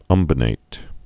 (ŭmbə-nāt, ŭm-bōnĭt)